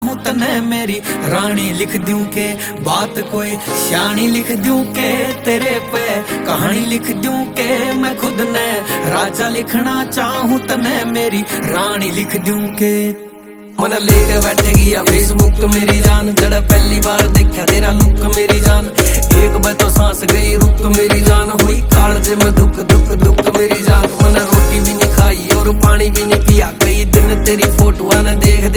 Haryanvi music